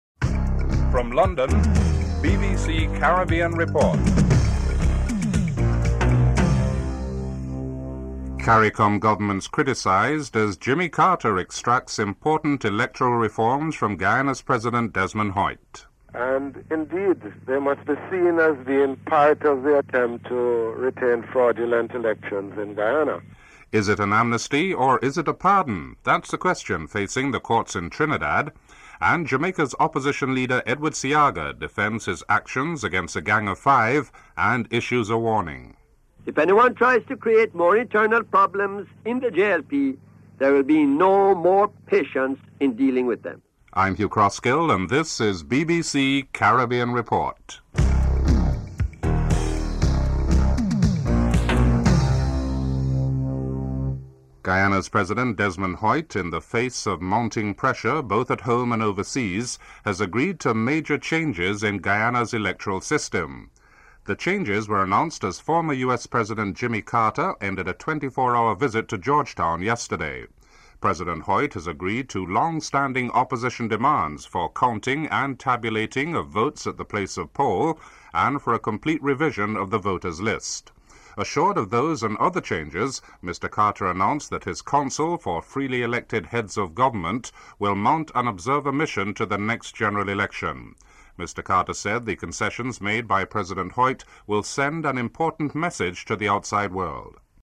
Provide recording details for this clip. The British Broadcasting Corporation